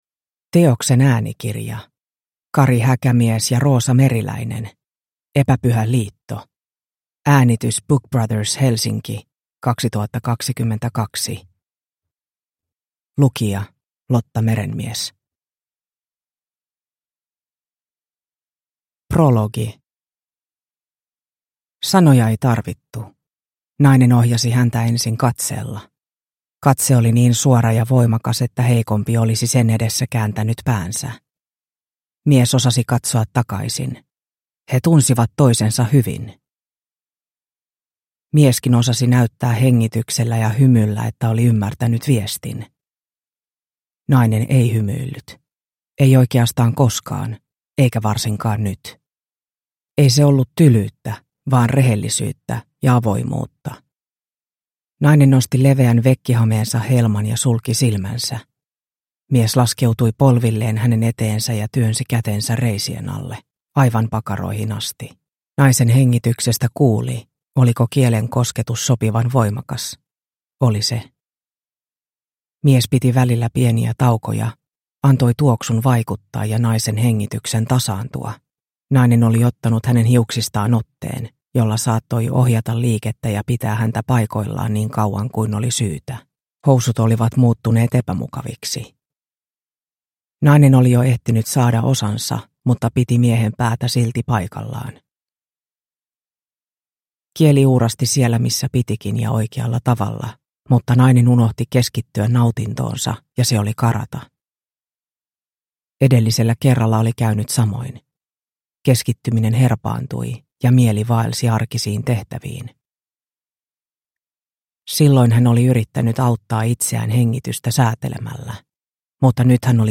Epäpyhä liitto – Ljudbok – Laddas ner